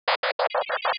The stripes that now run vertically sound as noise bursts, and the
stars are now in the upper right (high-pitched on the right).
usflag90.wav